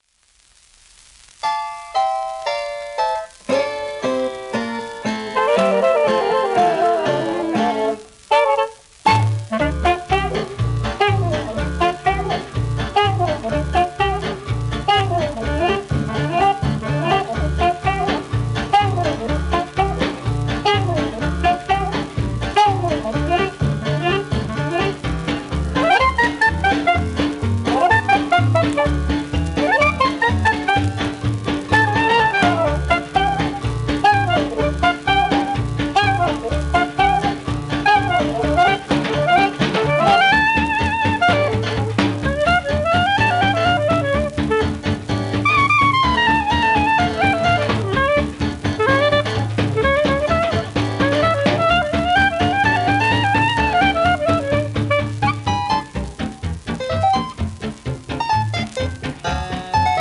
1940年録音
ハープシコードが入ったセクステット